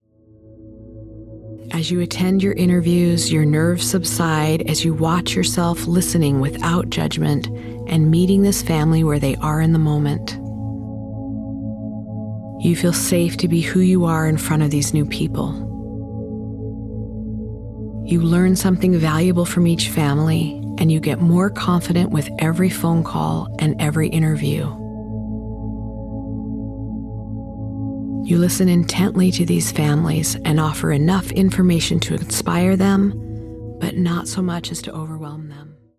Doula Business Meditation – sample
doula-bus-medidation-sample.mp3